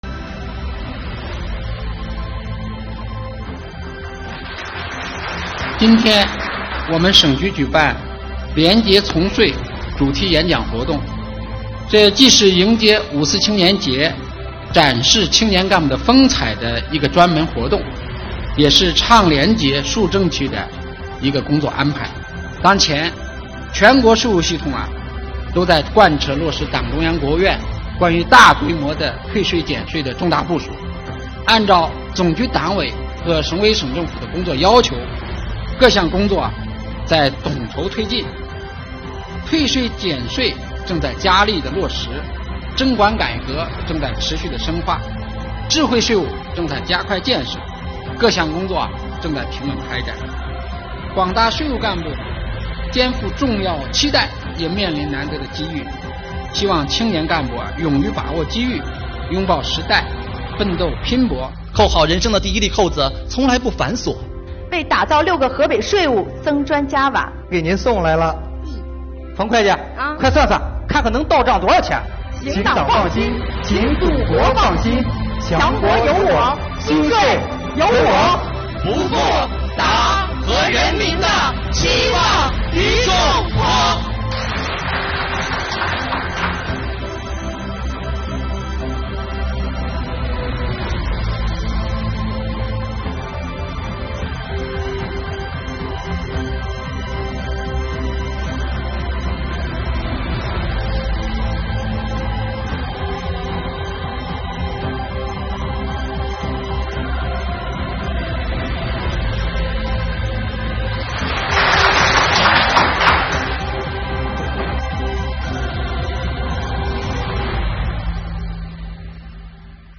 举行青年廉政主题演讲比赛
活动当天，来自各青年理论学习小组的12组选手通过演讲、朗诵和舞台剧等形式，依托丰富感人的真实案例，紧密结合自身实际，真挚生动地展示了河北税务青年干部在干事创业、履职尽责、遵规守纪方面的学习和实践成果，向未来发出了属于这一代青年人的青春宣言，为喜迎党的二十大胜利召开和建团百年献礼。